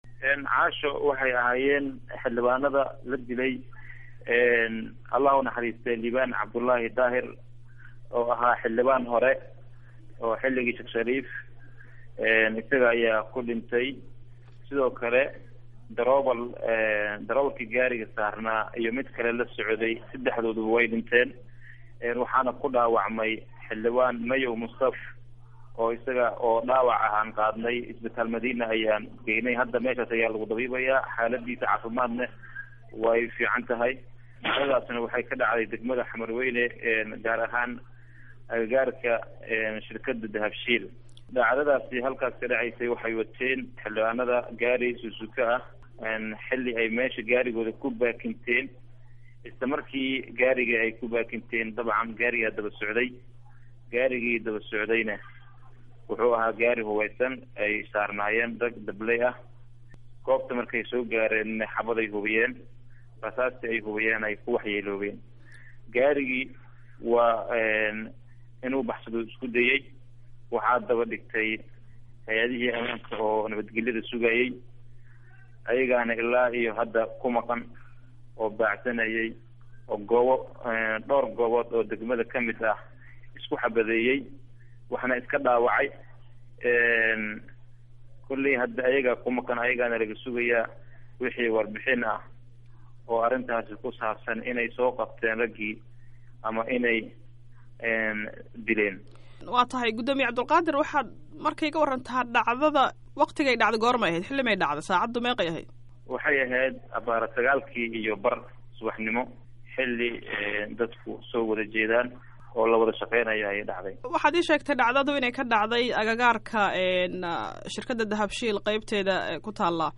Waraysiga Weerarka Xildhibaanada